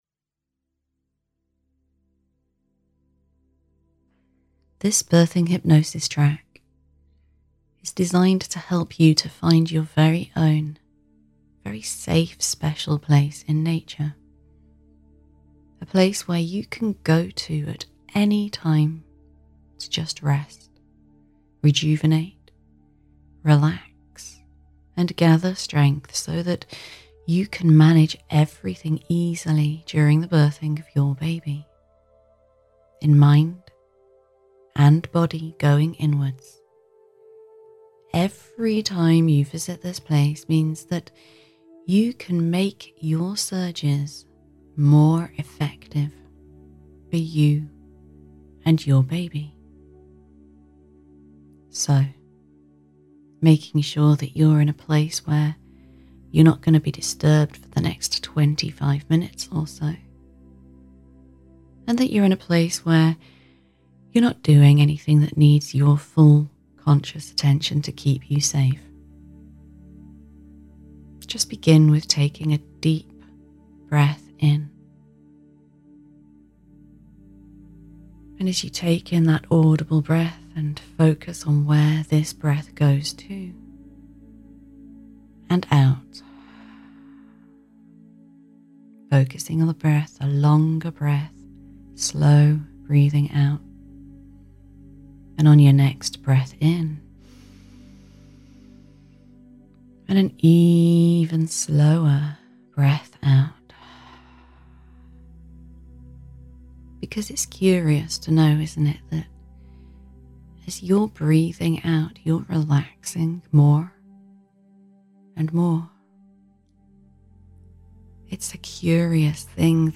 Free Hypnobirthing Track